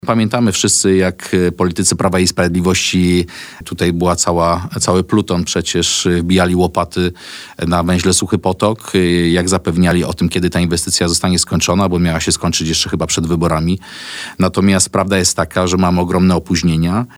Tematy dwóch inwestycji infrastrukturalnych pojawiły się w naszej rozmowie z wiceministrem Przemysławem Koperskim.
Dziś w porannym programie rozmawialiśmy z nowym wiceministrem infrastruktury, Przemysławem Koperskim.